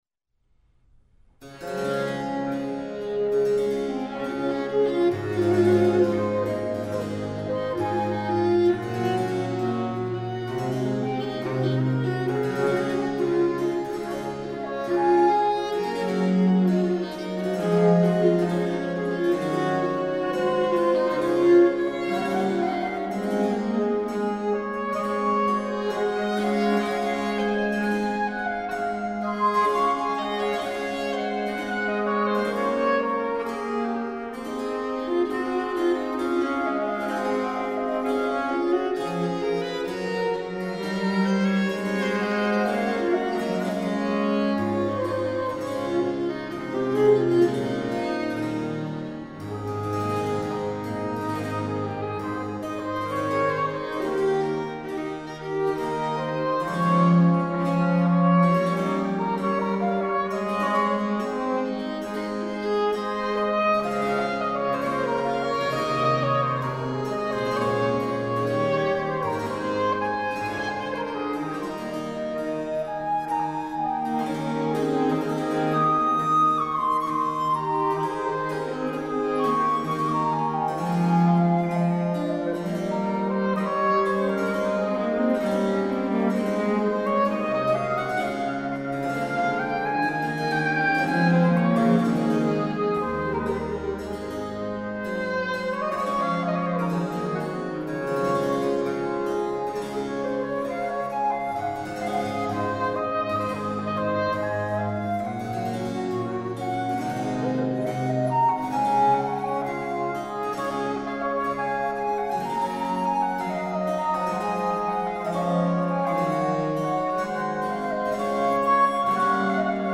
Quartett D-Dur für Traversflöte, Oboe, Viola und Basso Continuo
Satz Adagio e Mesto (rechte Maustaste)